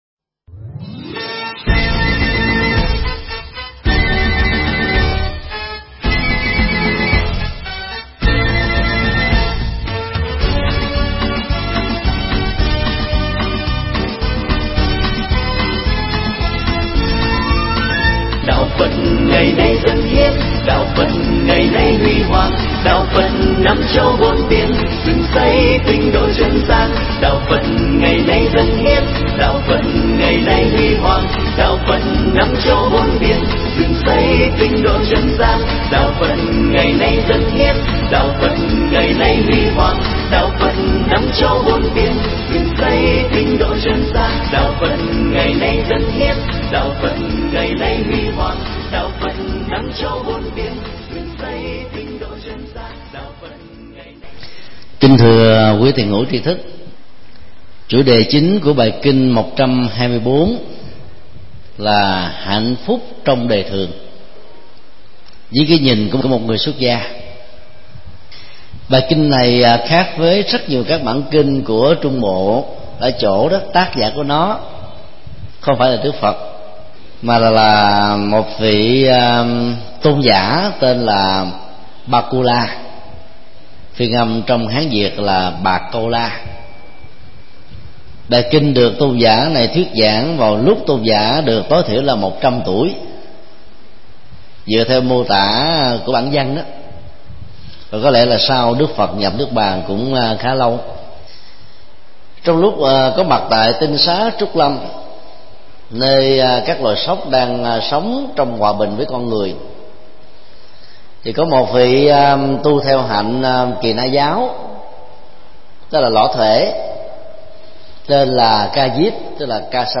Mp3 Pháp thoại Kinh Trung Bộ 124
Chùa Xá Lợi